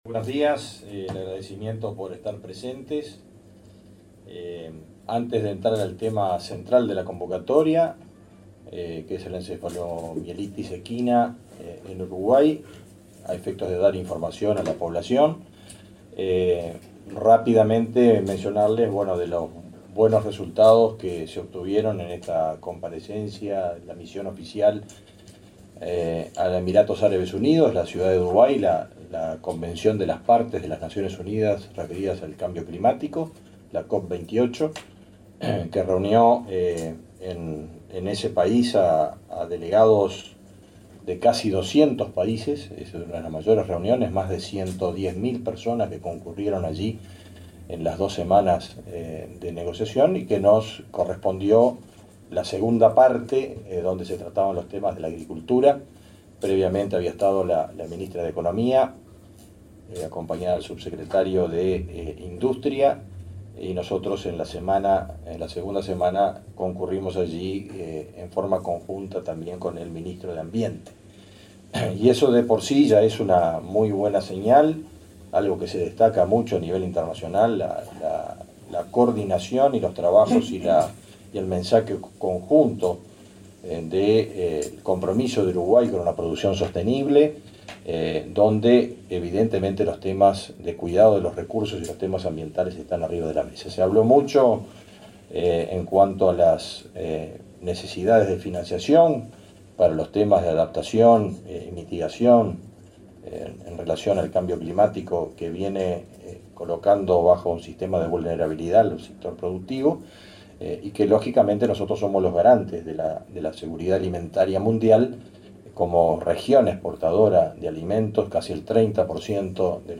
Conferencia de autoridades en el Ministerio de Ganadería
Conferencia de autoridades en el Ministerio de Ganadería 13/12/2023 Compartir Facebook X Copiar enlace WhatsApp LinkedIn El ministro de Ganadería, Fernando Mattos; el director de Servicios Ganaderos, Diego de Freitas, y el director nacional de Emergencias, Santiago Caramés, brindaron una conferencia de prensa para informar sobre la situación de la encefalomielitis equina en el país.